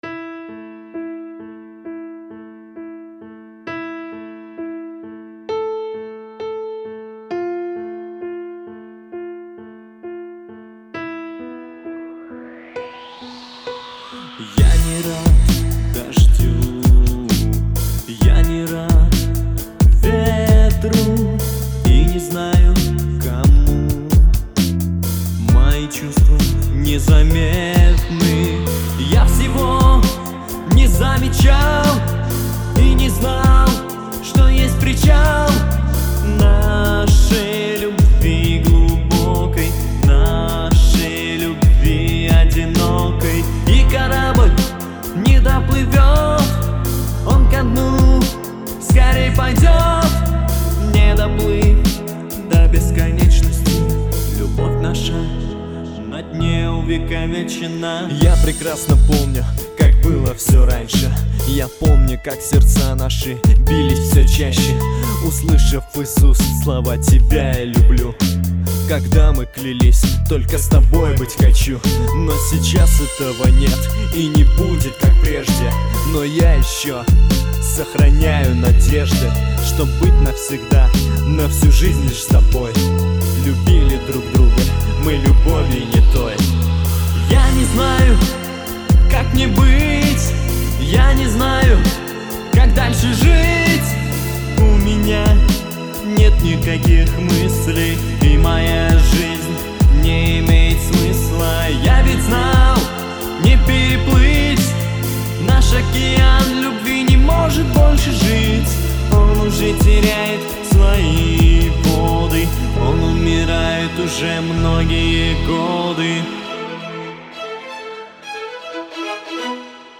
Океан любви (Ремикс